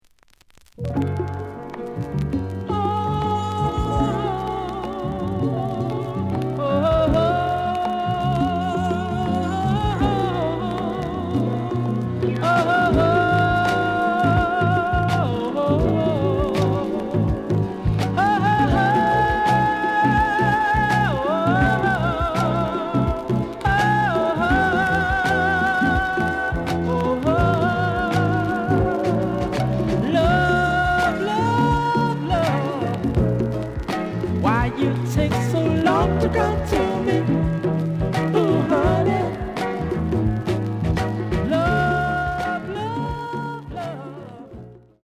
The audio sample is recorded from the actual item.
●Genre: Soul, 70's Soul
Slight noise on both sides.)